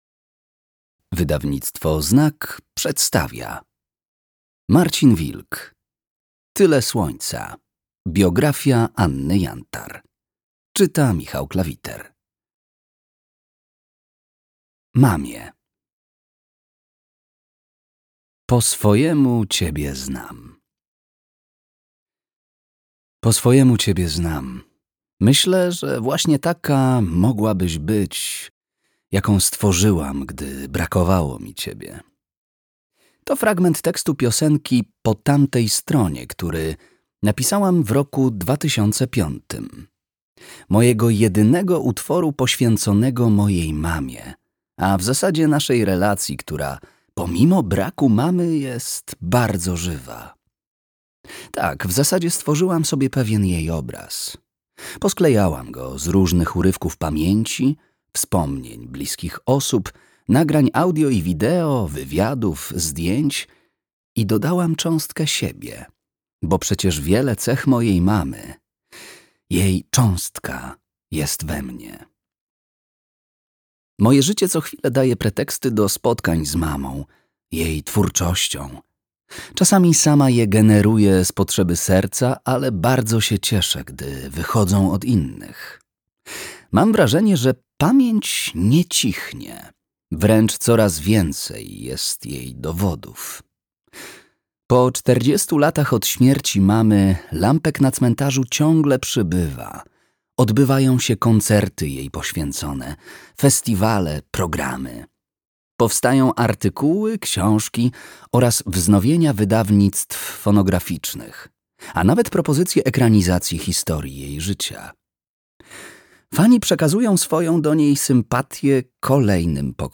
Tyle słońca. Anna Jantar. Biografia - Marcin Wilk - audiobook